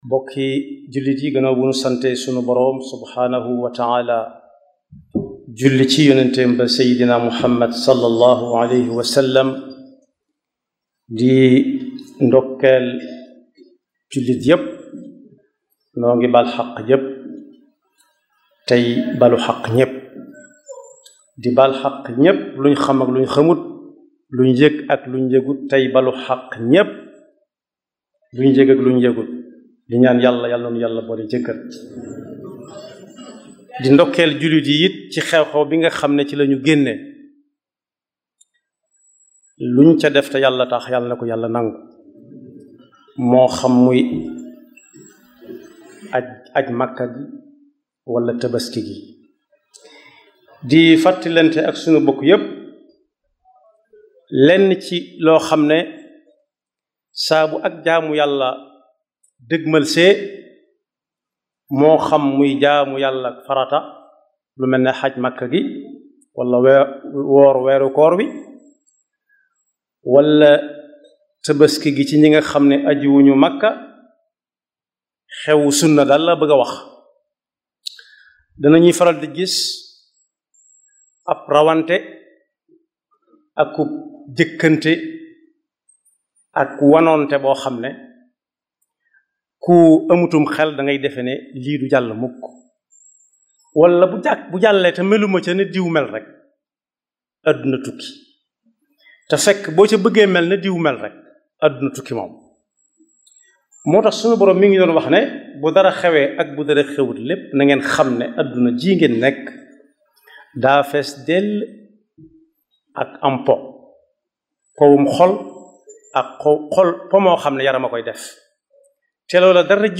Les Khoutba